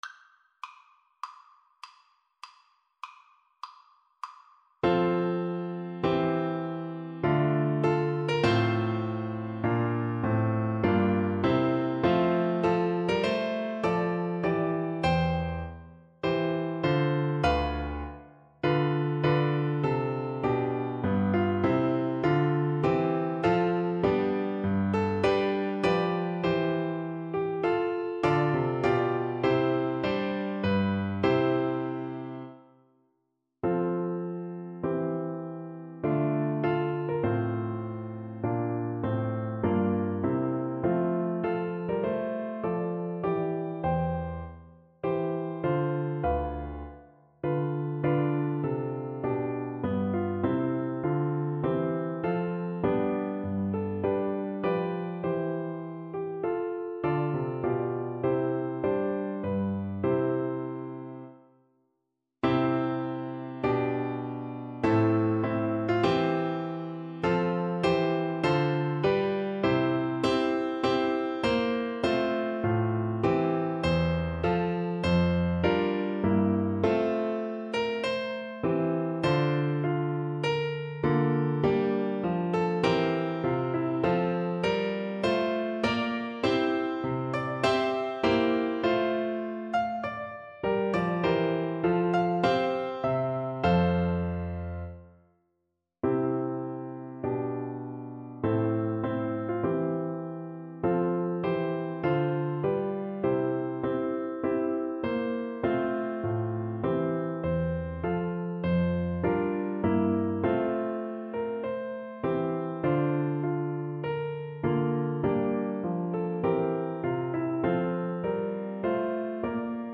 Play (or use space bar on your keyboard) Pause Music Playalong - Piano Accompaniment Playalong Band Accompaniment not yet available transpose reset tempo print settings full screen
Trombone
F major (Sounding Pitch) (View more F major Music for Trombone )
I: Largo
4/4 (View more 4/4 Music)
Classical (View more Classical Trombone Music)